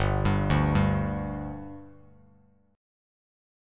Listen to G5 strummed